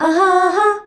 AHAAA   E.wav